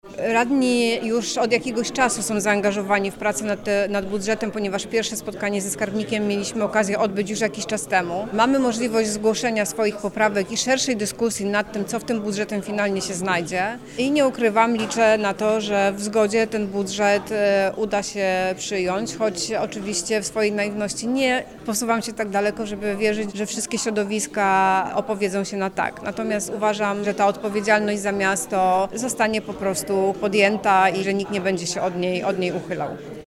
Przewodnicząca Rady Miejskiej Wrocławia Agnieszka Rybczak przypomina, że decyzję ws. ostatecznego kształtu budżetu rajcy miejscy podejmą poprzez głosowanie.